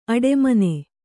♪ aḍemane